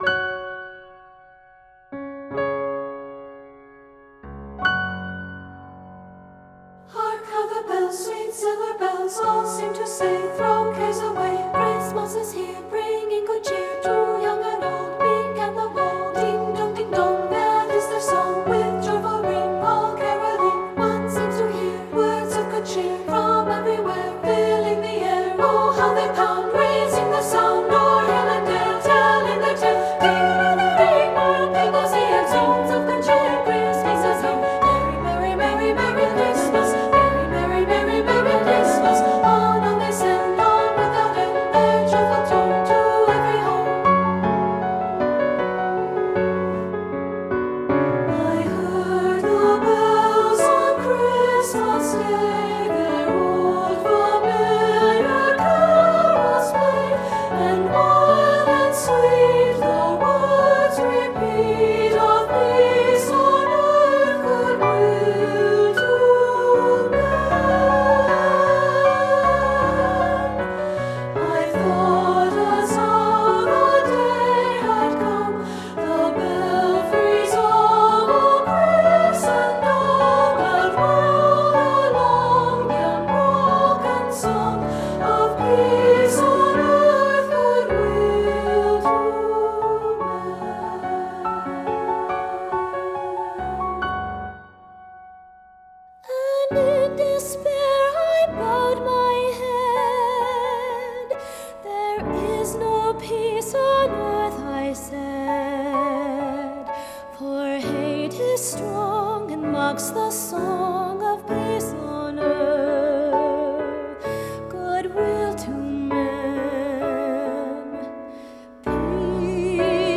• Voice 1 (Part )
• Voice 2 (Part)
• Piano
Studio Recording
Ensemble: Unison and Two-Part Chorus
Key: A major
Accompanied: Accompanied Chorus